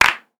R - Foley 217.wav